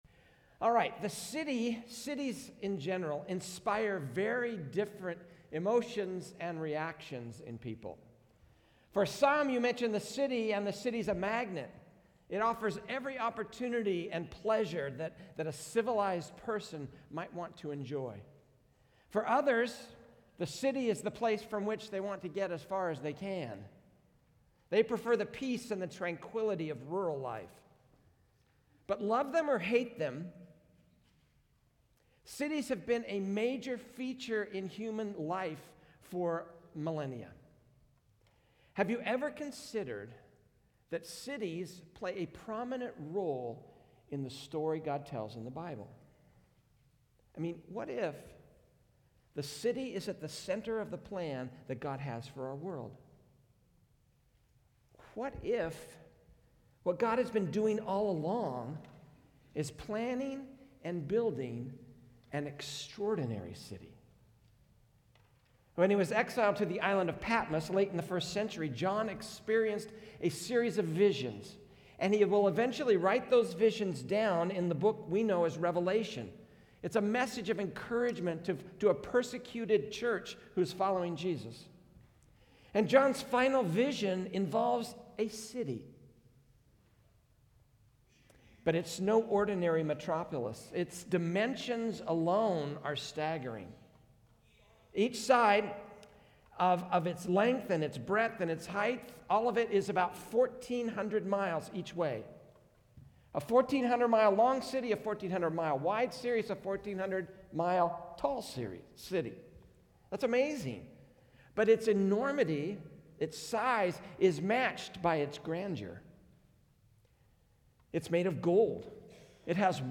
Sermon Archive - Peninsula Community Church